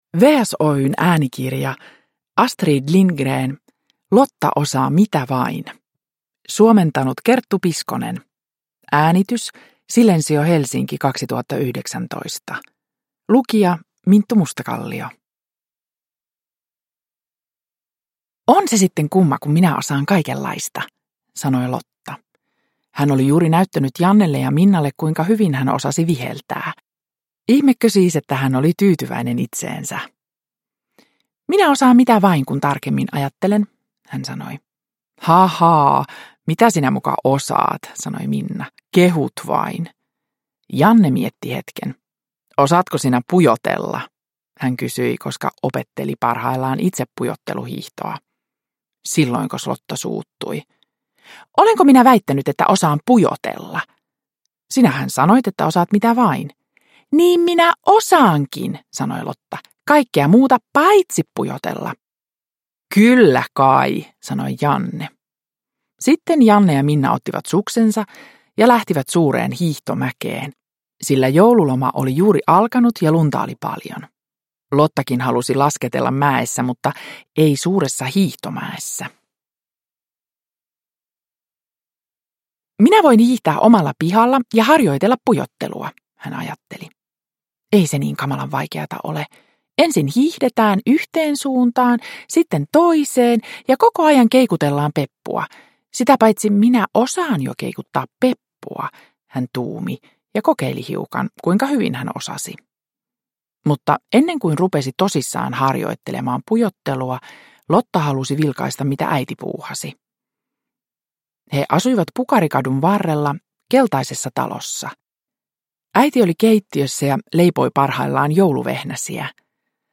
Lotta osaa mitä vain – Ljudbok – Laddas ner